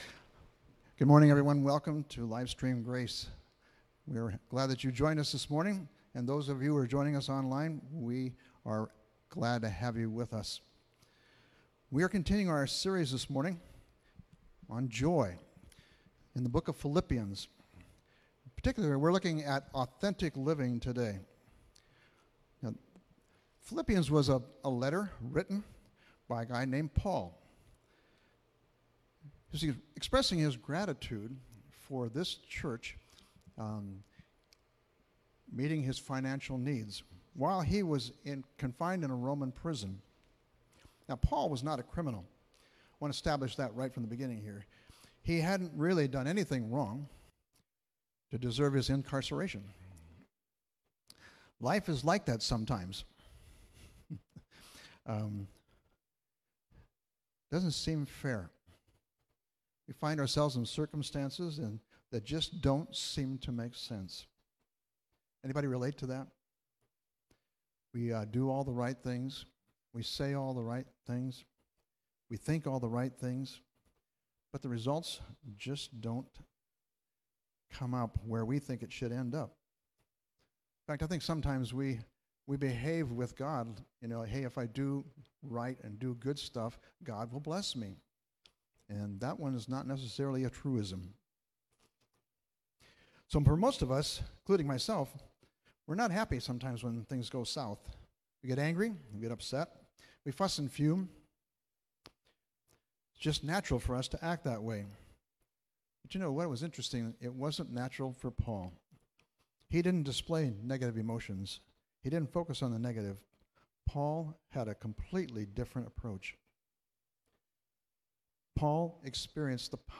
Jun 14, 2020 Authentic Living (06.14.2020) MP3 PDF SUBSCRIBE on iTunes(Podcast) Notes Discussion Sermons in this Series The power of the Gospel brings joy by completely changing our lives. Paul shows how the Gospel changed his view of purpose, conduct, and unity.